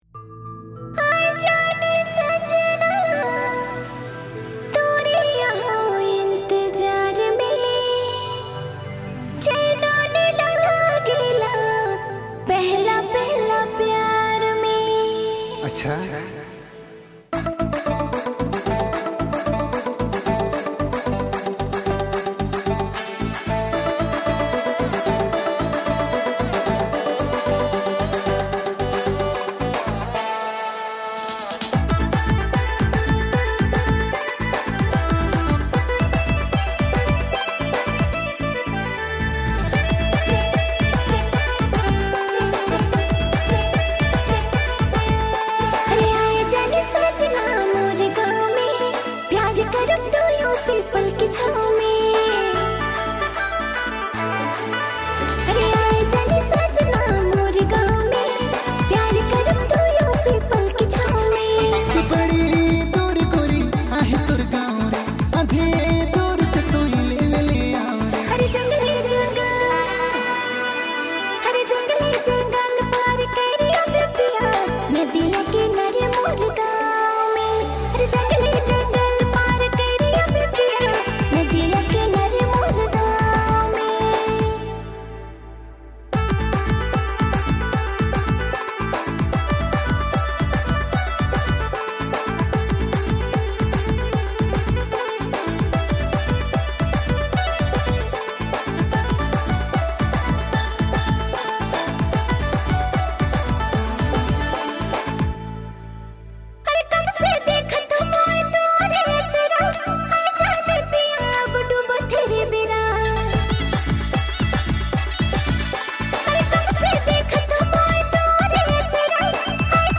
New Nagpuri No Voice Dj Song